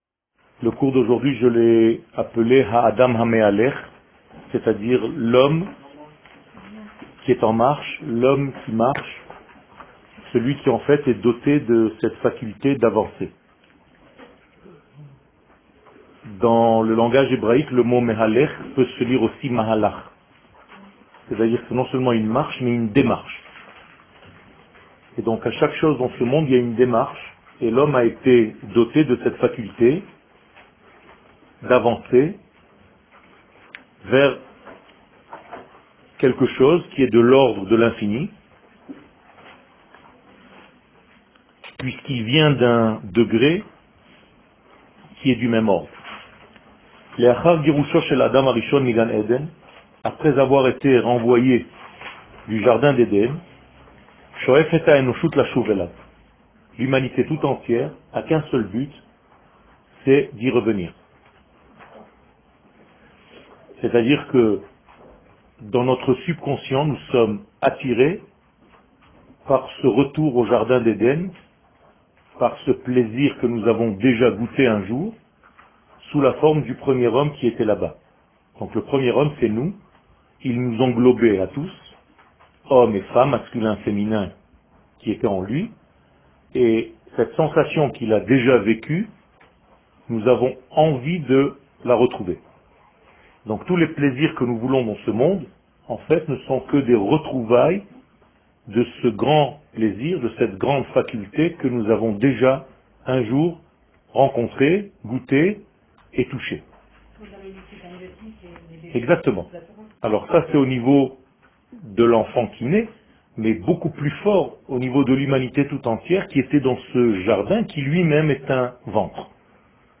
L'homme qui marche Eretz Israel שיעור מ 21 נובמבר 2017 01H 07MIN הורדה בקובץ אודיו MP3 (11.6 Mo) הורדה בקובץ אודיו M4A (8.04 Mo) TAGS : Secrets d'Eretz Israel Torah et identite d'Israel שיעורים קצרים